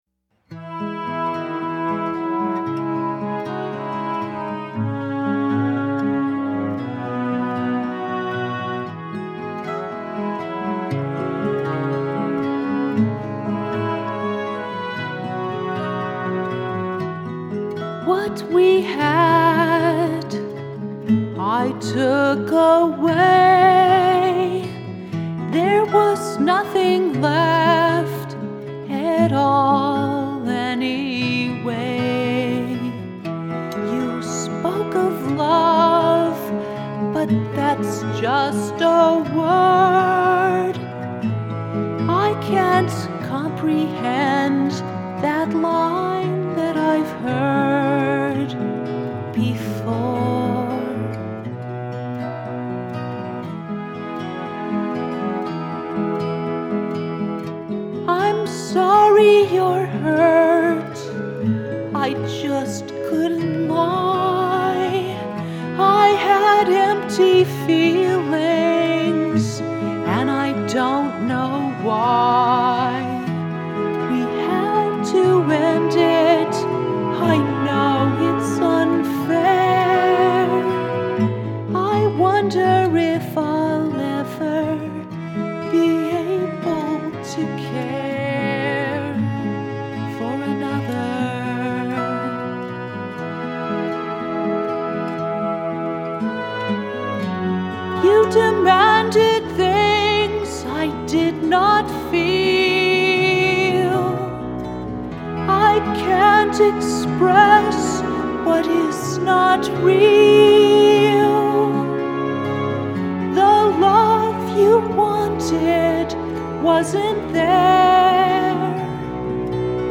When this song was recorded, I was still learning to sing after 30 years of silence: